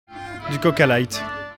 prononciation Du coca-light ↘ explication Les gens qui changent tout le temps de sujet au point que ça en devient assommant, (on dit qu’) ils passent du coca-light.